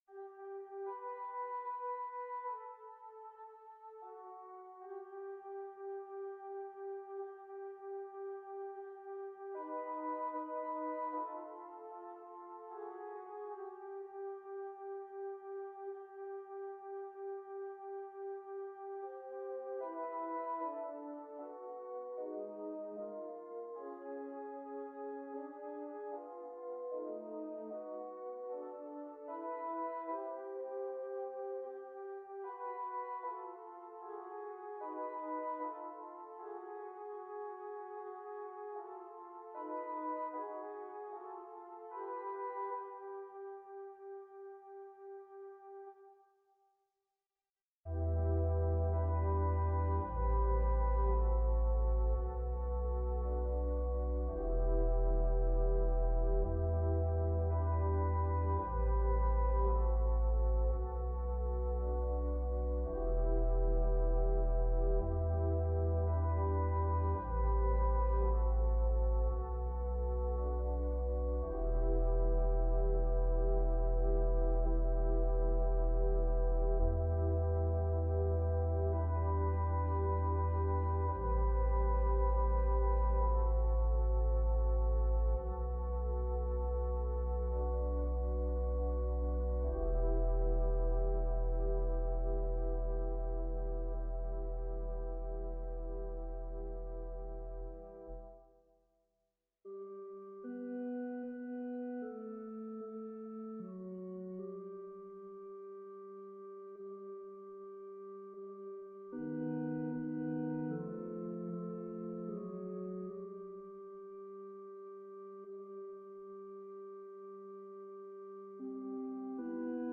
An organ solo arrangement